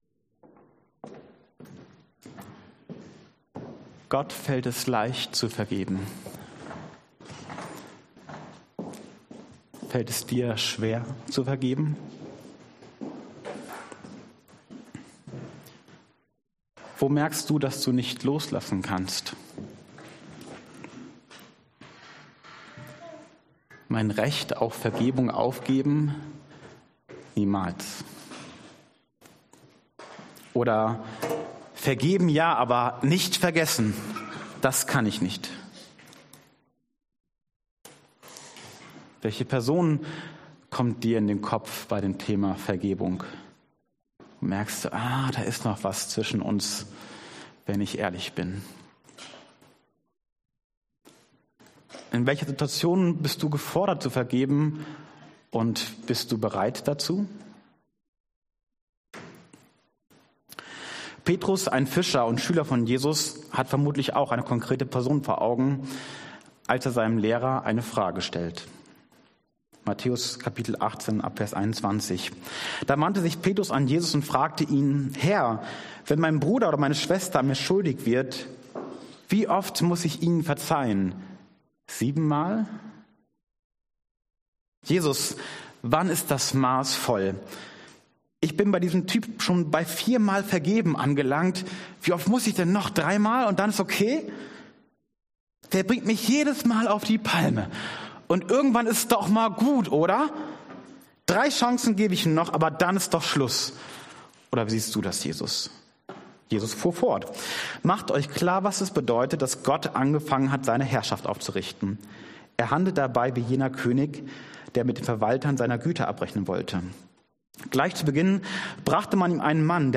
Dienstart: Predigt Themen: Vergebung